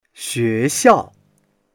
xue2xiao4.mp3